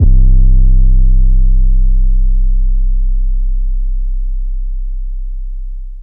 808 [wondagurl].wav